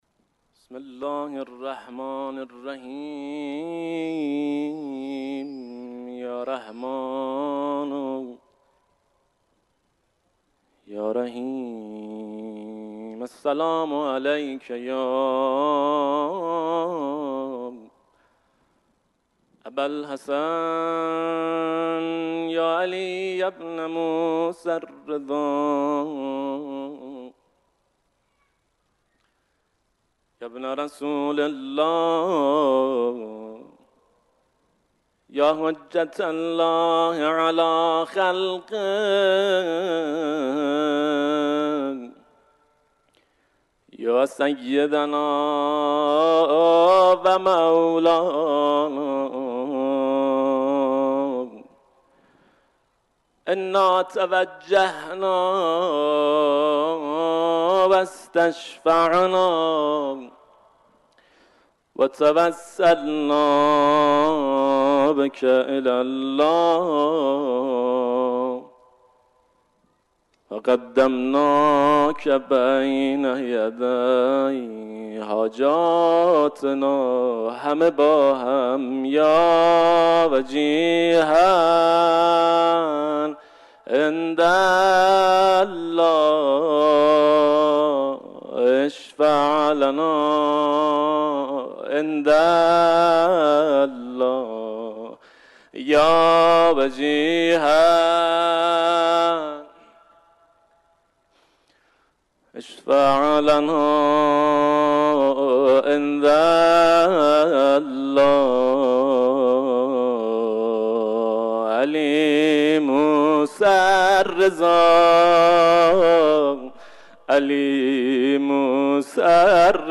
مداحی در حرم مطهر امام رضا علیه السلام از صحن جامع